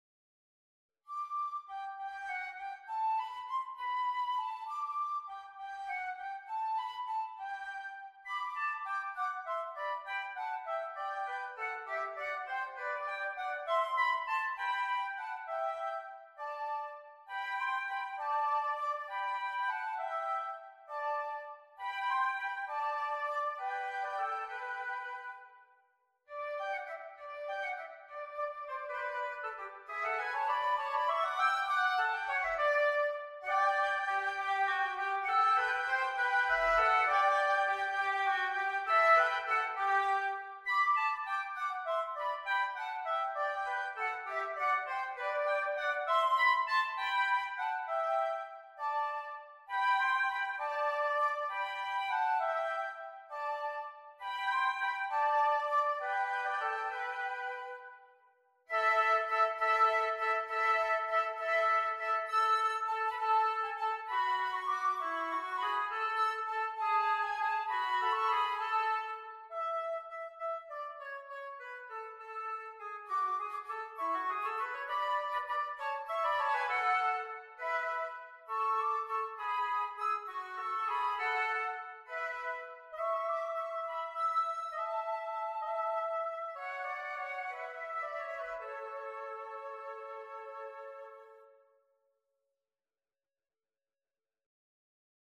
a charming French carol arranged for flute/oboe duet